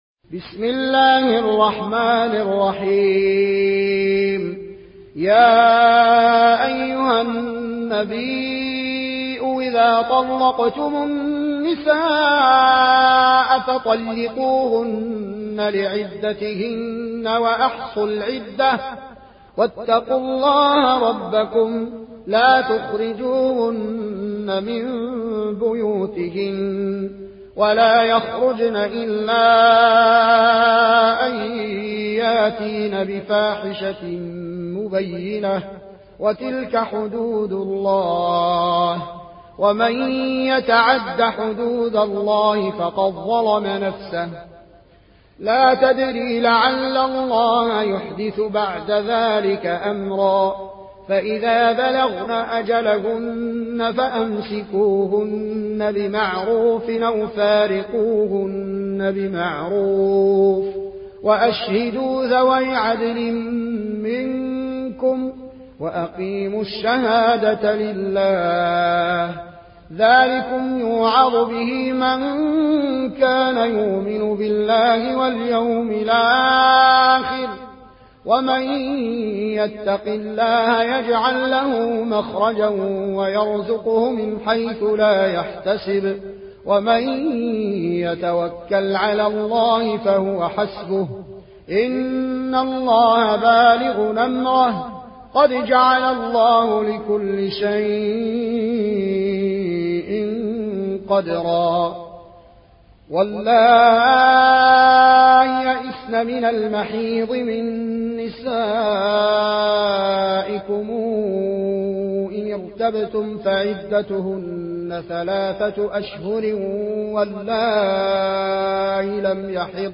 উপন্যাস Warsh থেকে Nafi